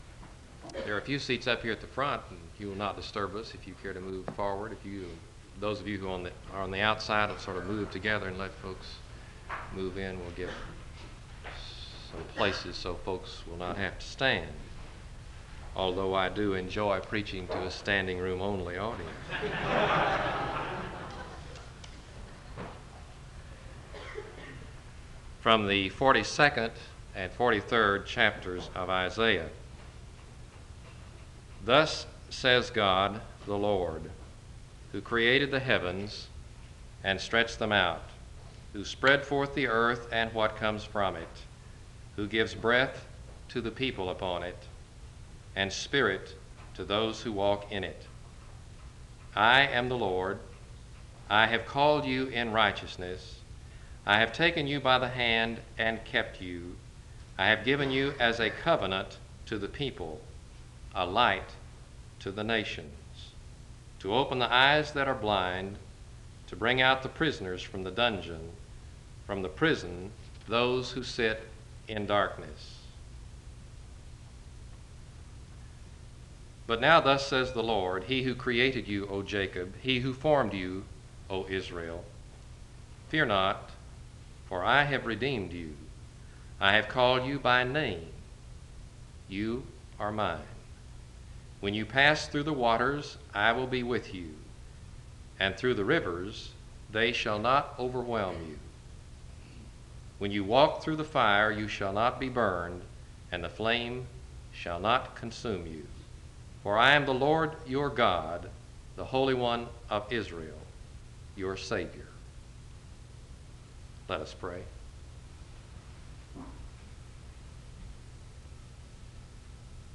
SEBTS Chapel
Wake Forest (N.C.)